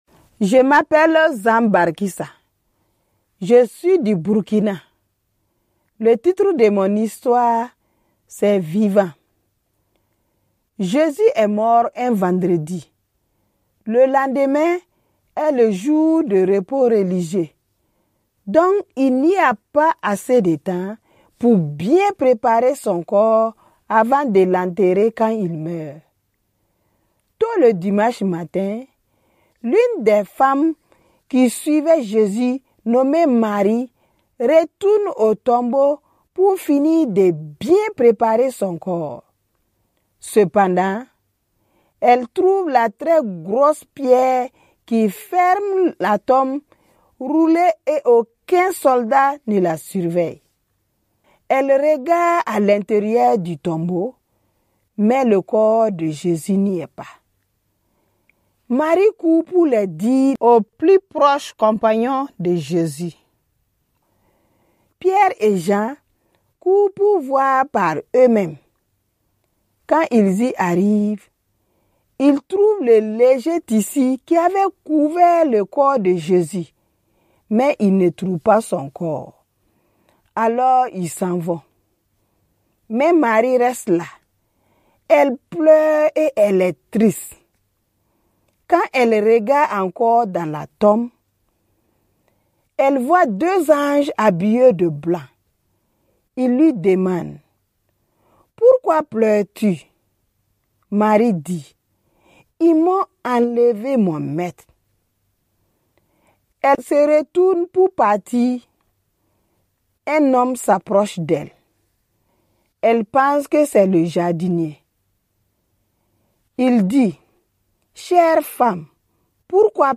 nous raconter comment Jésus a transformé Sa souffrance en bonne nouvelle.